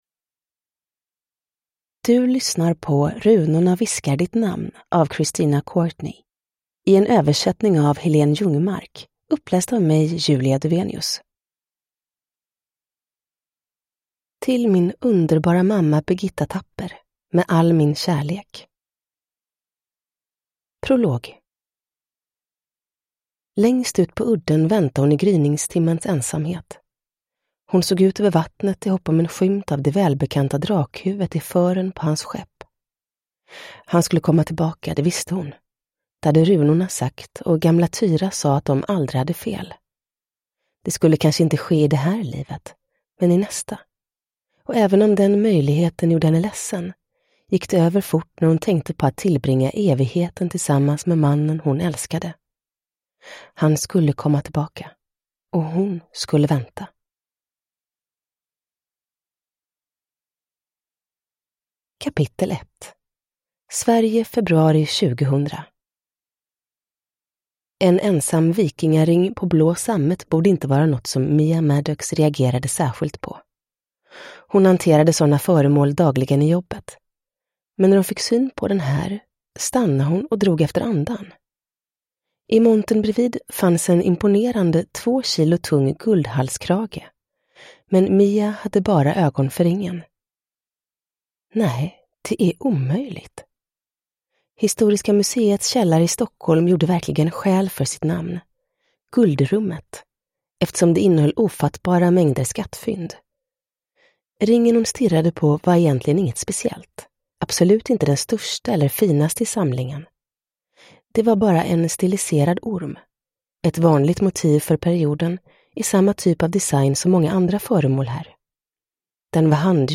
Runorna viskar ditt namn – Ljudbok
Uppläsare: Julia Dufvenius